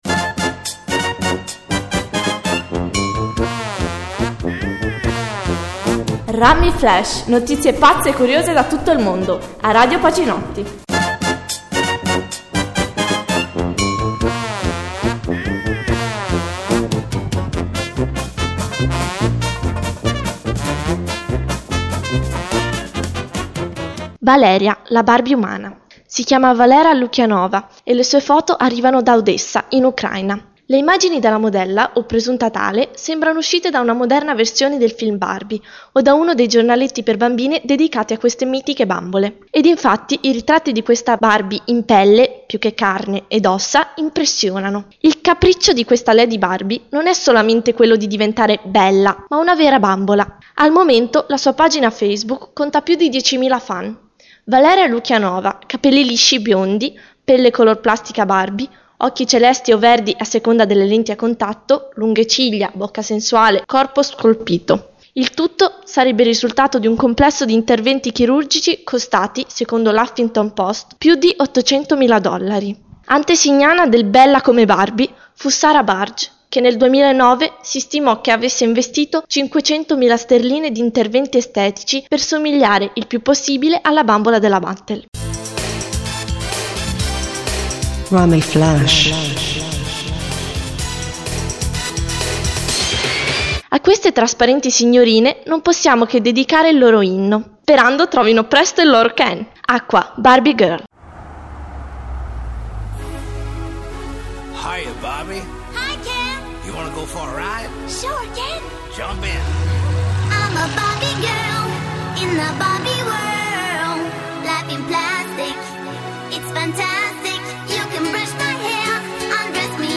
Notizie curiose e brano di musica con relazione alla notizia